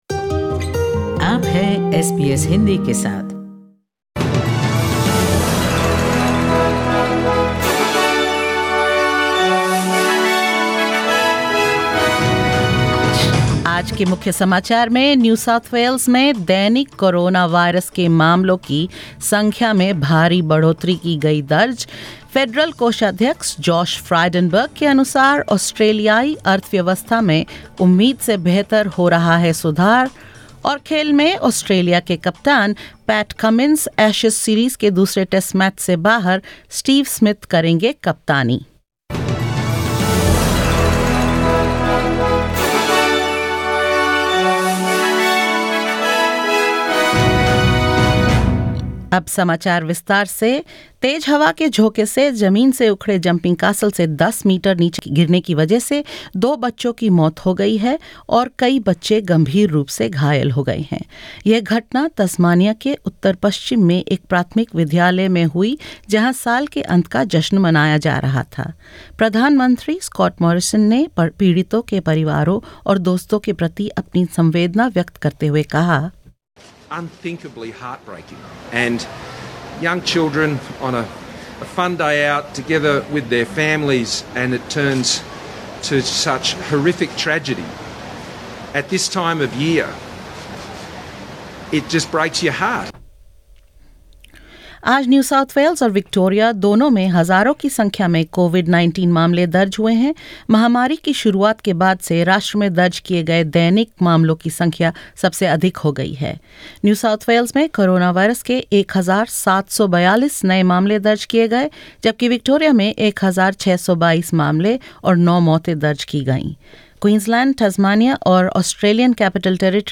SBS Hindi news bulletin